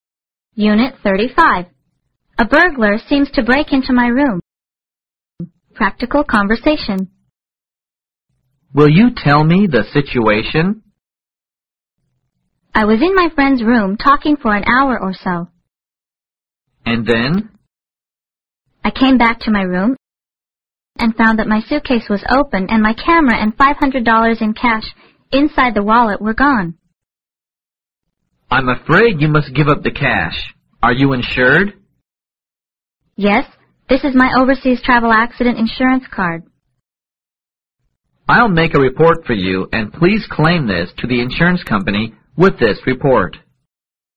Practical conversation